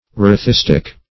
erethistic - definition of erethistic - synonyms, pronunciation, spelling from Free Dictionary Search Result for " erethistic" : The Collaborative International Dictionary of English v.0.48: Erethistic \Er`e*this"tic\, a. [Gr.